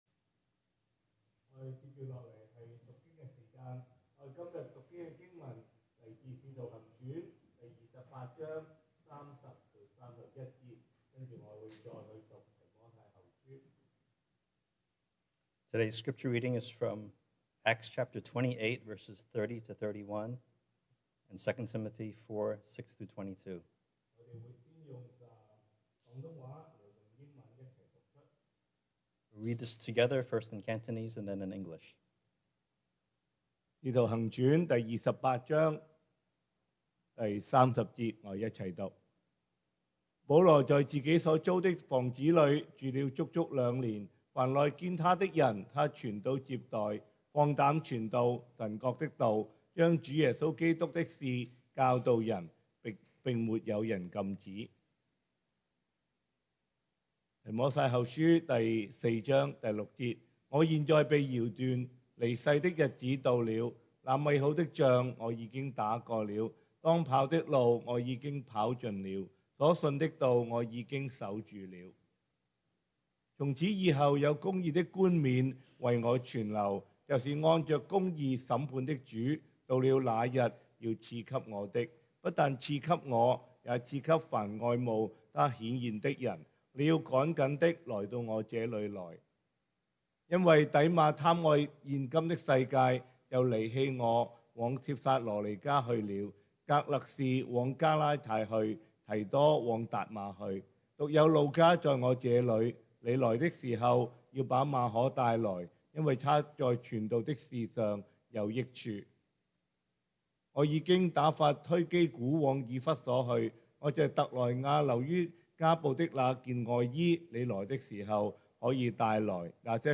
Passage: Acts 28:30-31, 2 Timothy 4:6-22 Service Type: Sunday Morning